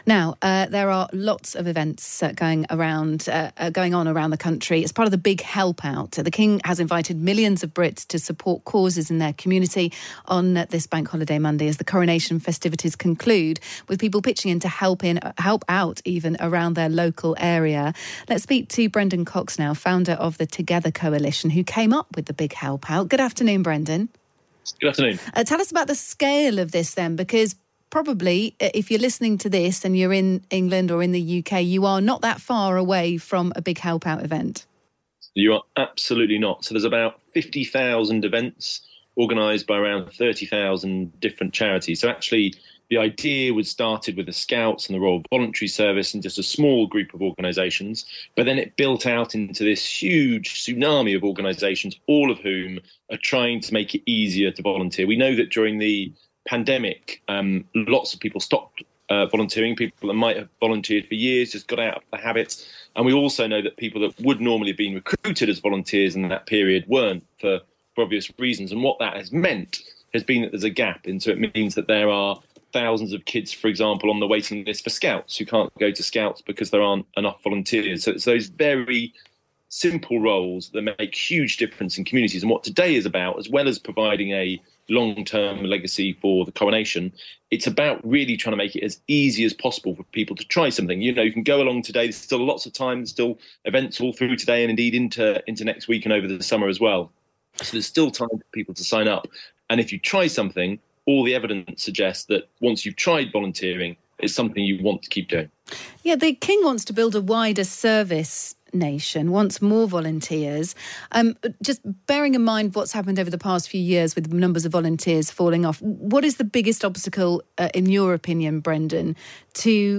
The Times Radio interview, for The Big Help Out